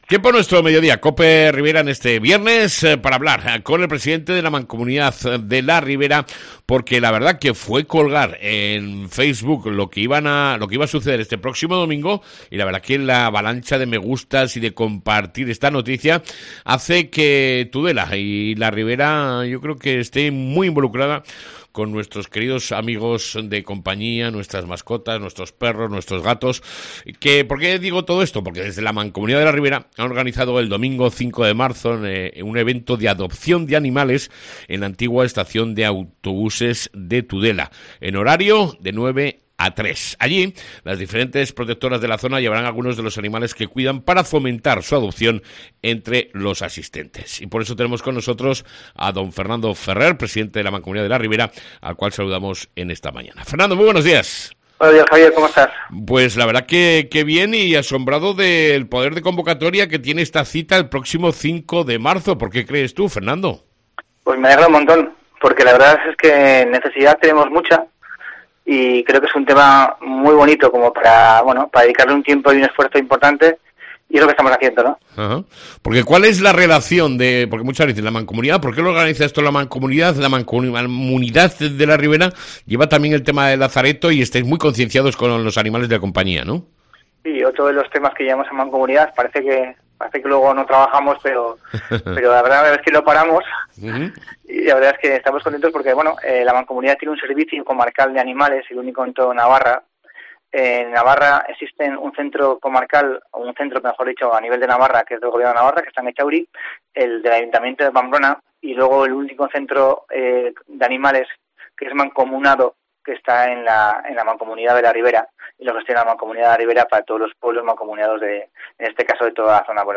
ENTREVISTA CON EL PRESIDENTE DE LA MANCOMUNIDAD, FERNANDO FERRER